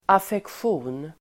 Ladda ner uttalet
Uttal: [afeksj'o:n]